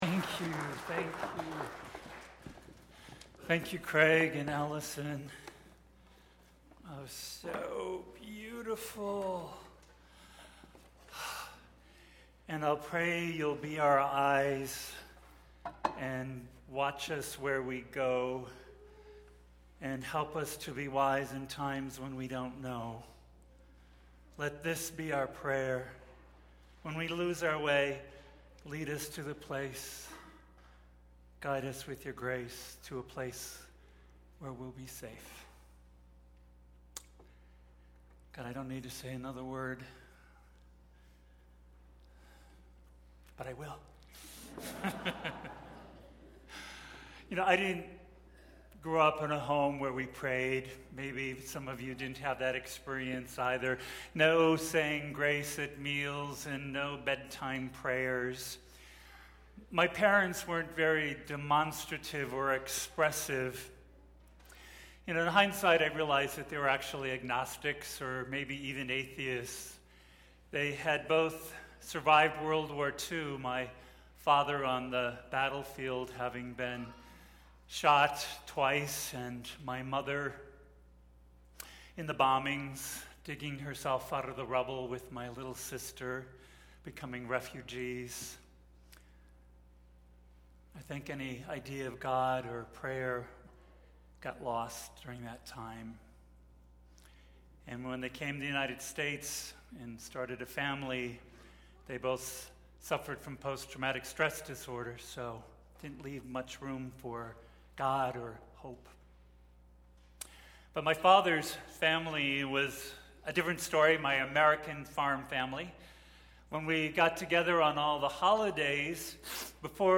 (This is the video clip from Eat, Pray, Love that is played during the message.)